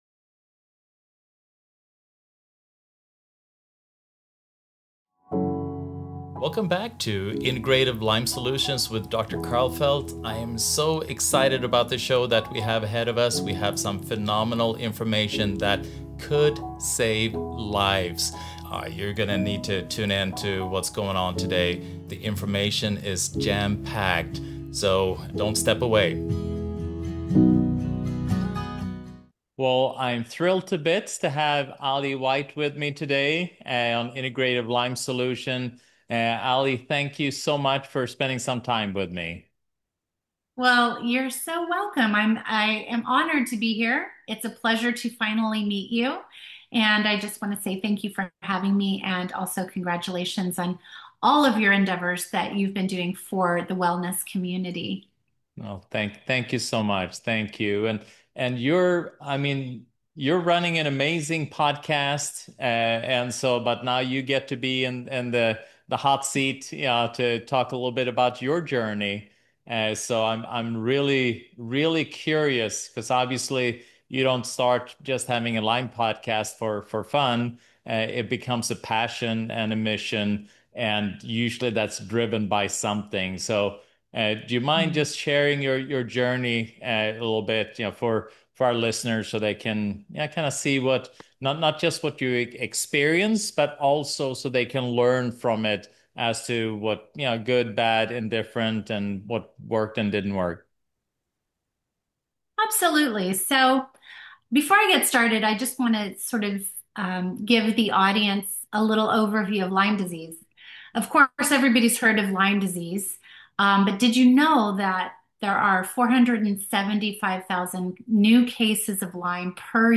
Understanding Lyme and Co-Infections: An In-Depth Interview